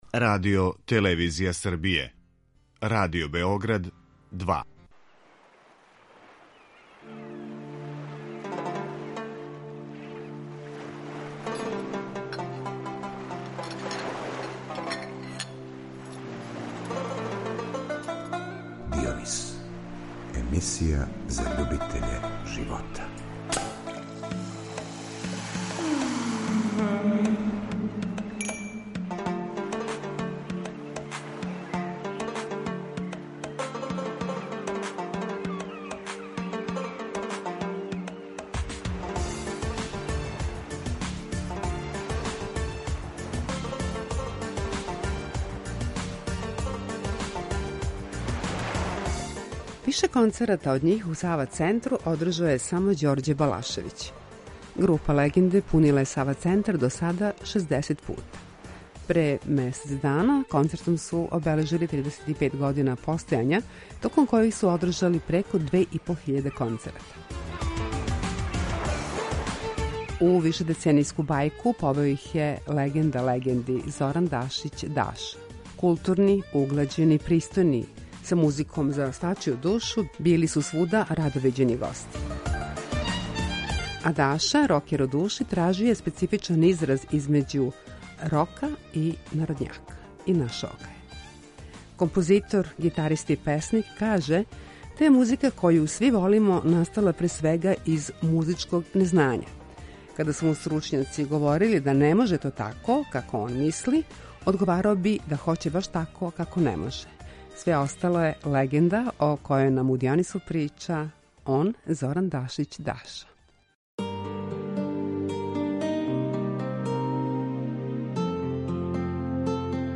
Све остало је легенда о којој нам у Дионису прича Зоран Дашић Даша .